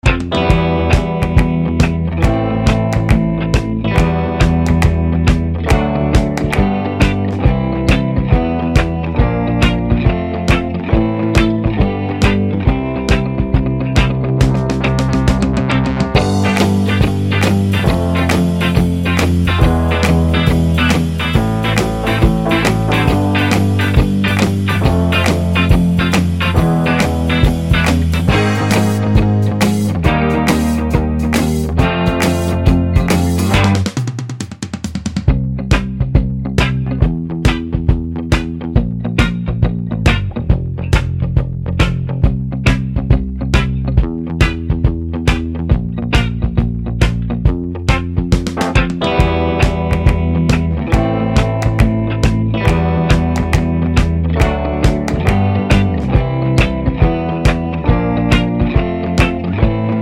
Minus Sax And Backing Vocals Pop (1980s) 2:57 Buy £1.50